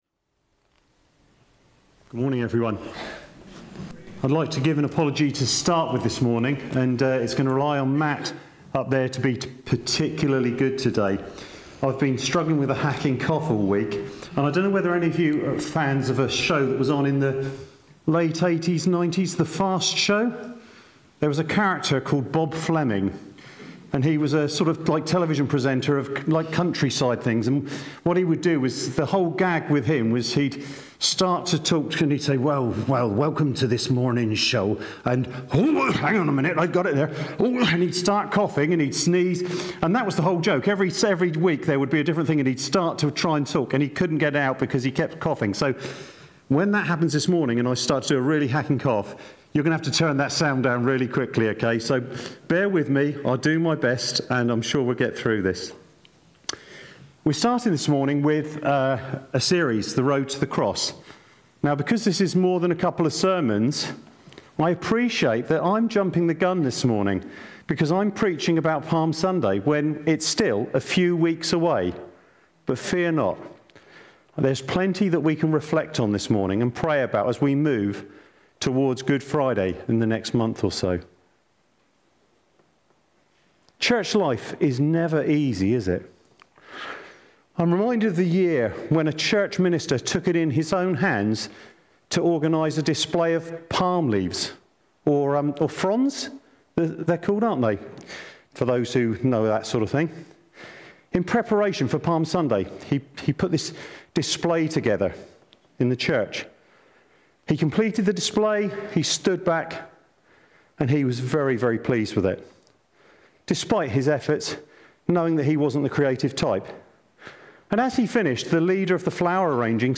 1st-March-2026-Road-to-the-Cross-Announced-Sermon-audio.mp3